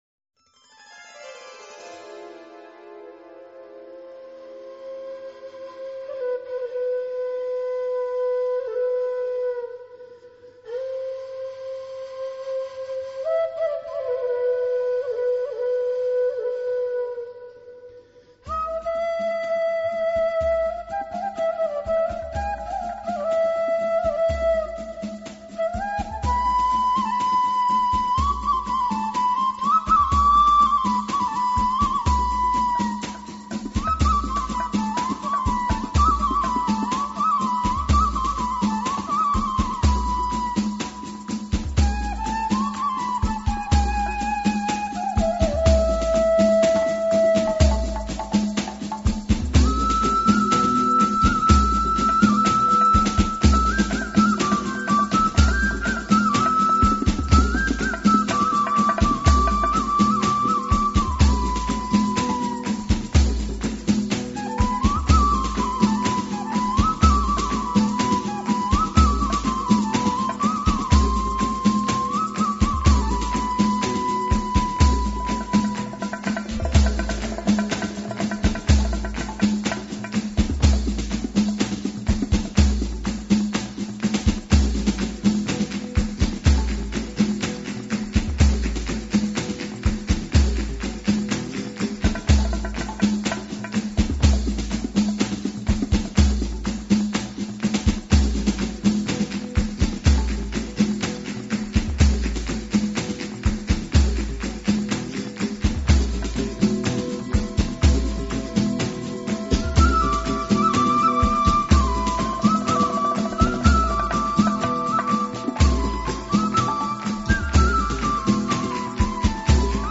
Нью эйдж New age Медитативная музыка